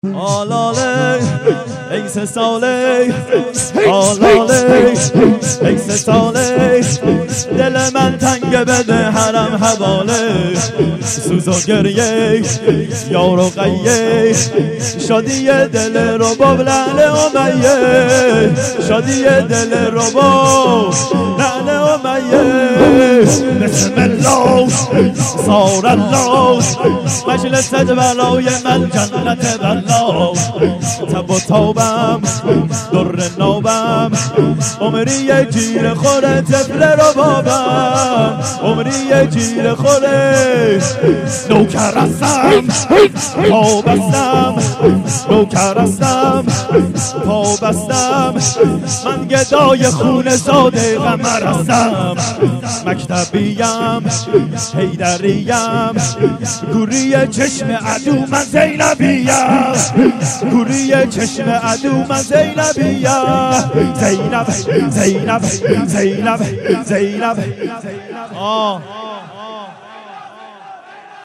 خیمه گاه - هیئت پنج تن آل عبا ساری - شب سوم محرم الحرام شور آلاله ای سه ساله